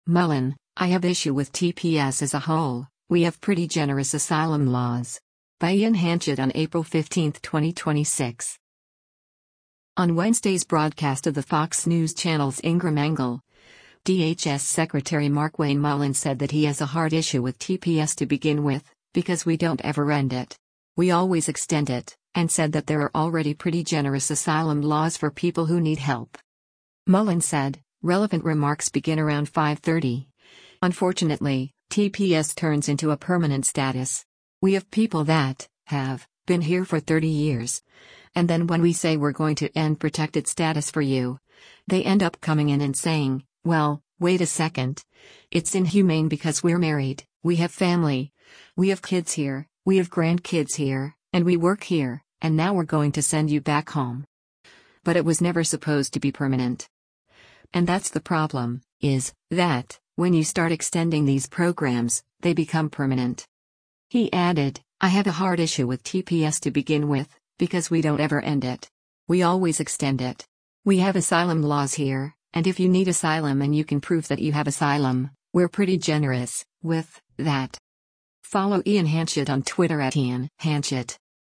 On Wednesday’s broadcast of the Fox News Channel’s “Ingraham Angle,” DHS Secretary Markwayne Mullin said that he has “a hard issue with TPS to begin with, because we don’t ever end it. We always extend it.” And said that there are already “pretty generous” asylum laws for people who need help.